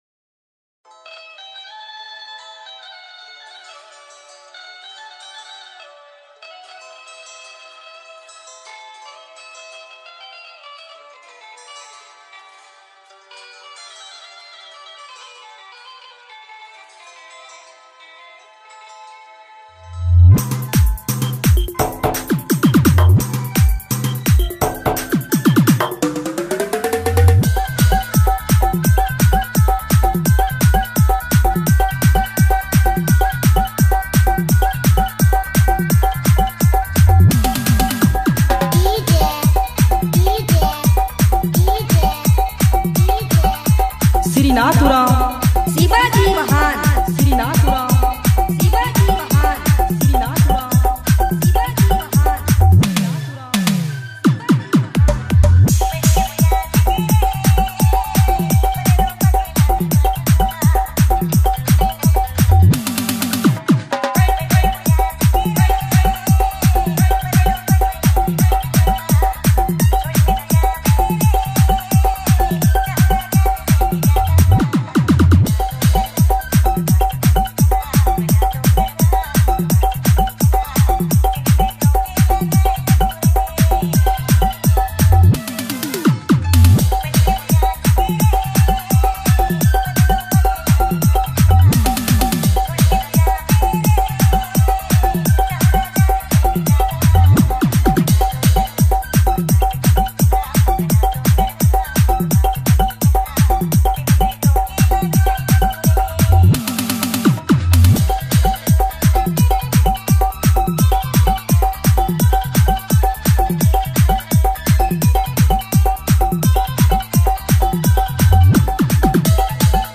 Dj Remixer
New Nagpuri Dj Song 2025